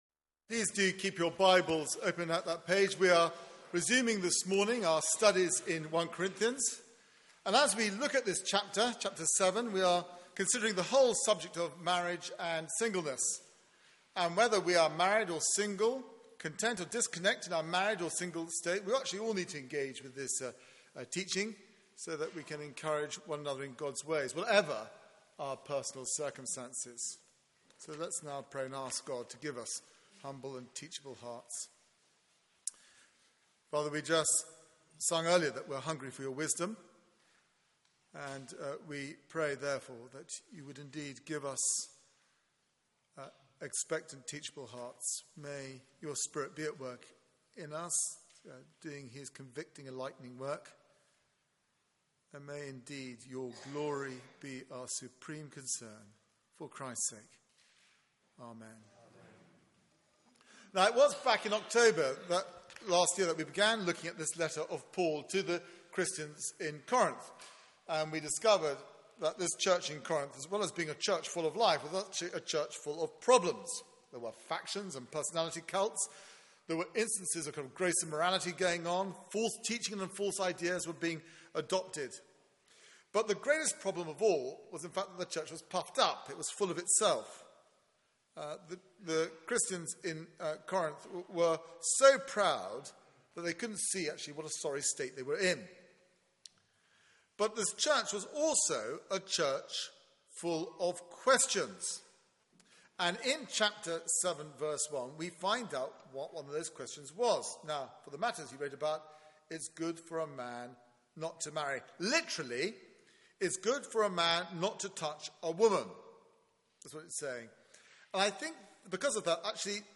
Media for 9:15am Service on Sun 26th Apr 2015
Theme: Marriage matters Sermon